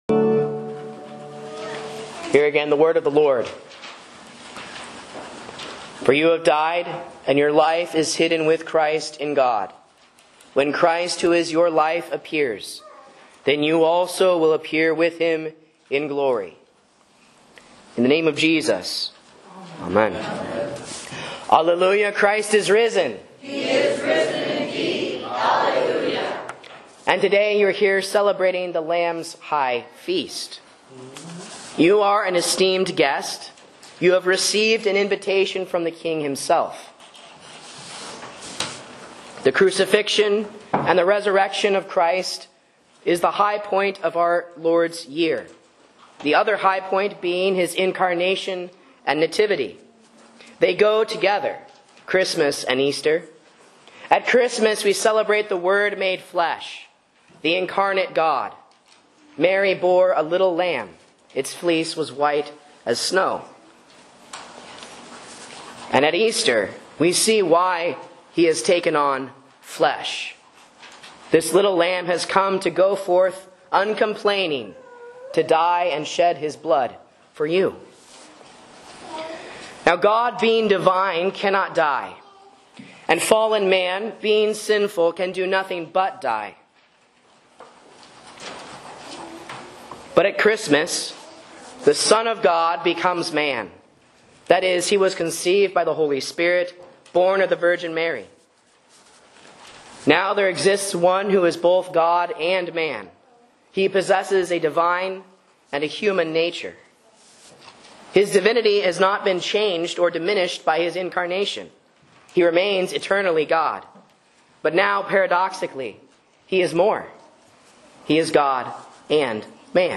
Sermon and Bible Class Audio from Faith Lutheran Church, Rogue River, OR
A Sermon on Colossians 3:3-4 for Easter